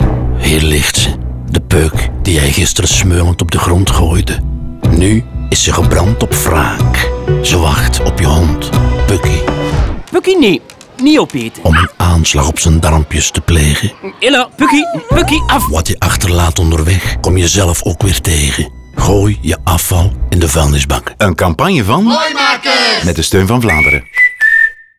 Radiospot Peuken